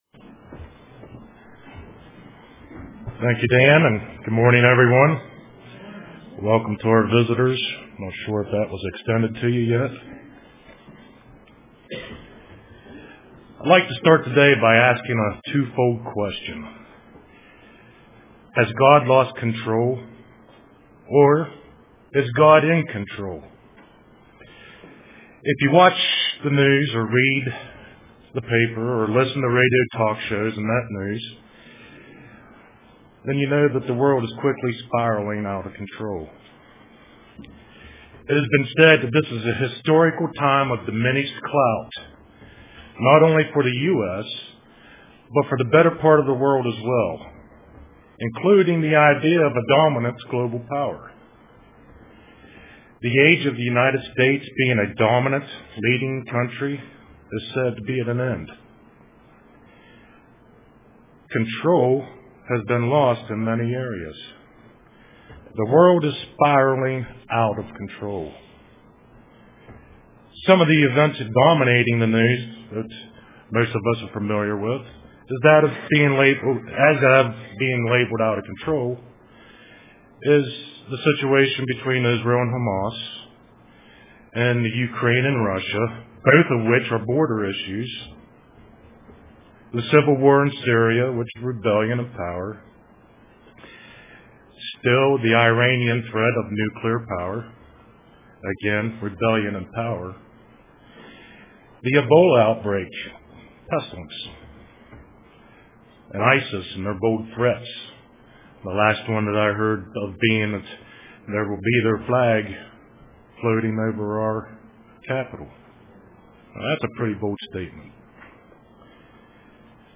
We must maintain our faith in God UCG Sermon Studying the bible?